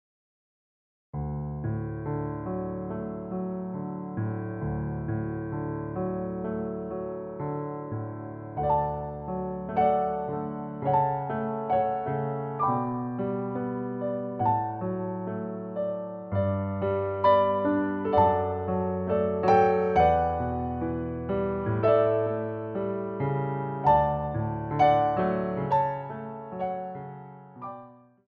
Port de Bras